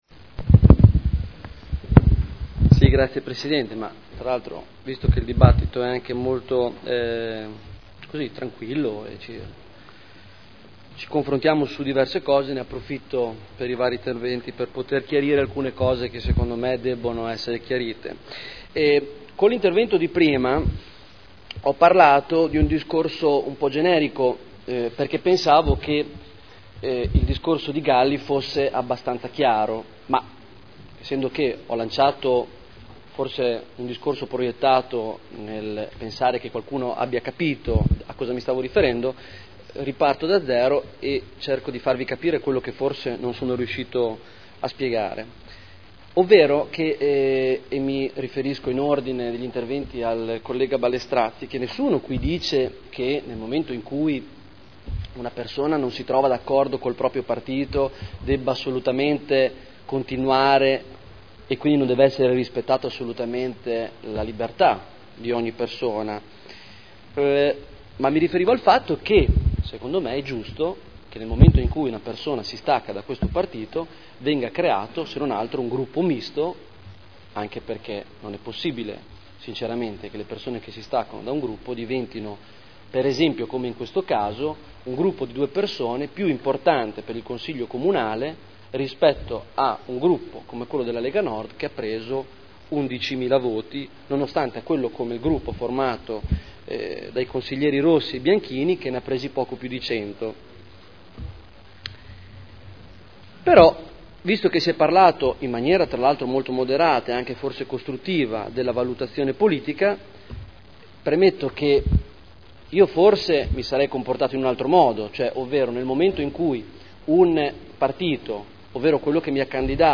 Seduta del 12 dicembre Commissione consiliare permanente Risorse Finanziarie, Umane, Strumentali e Affari Generali – Modifica Dibattito